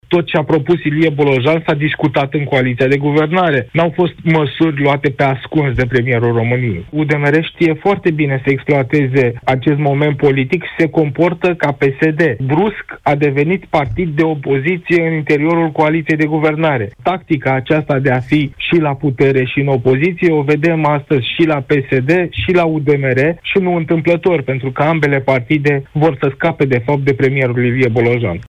într-o intervenție la matinalul EUROPA FM.